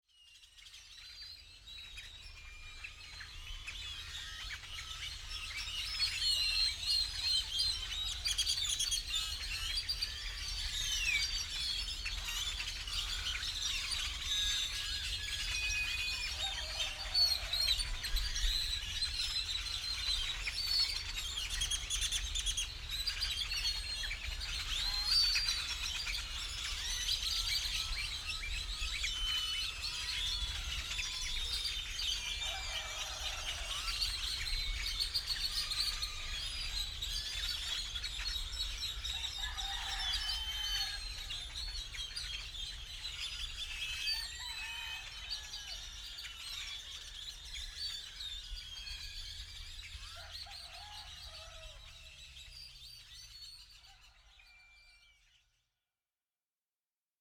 Paisaje acustico del amanecer en La Bocana, municipio de Copala, Estado de Guerrero, Mexico. Grabación de sonidos de aves y estruendo del mar (choque de las olas a distancia).
Equipo: Grabadora digital - Micrófonos Sennhesier.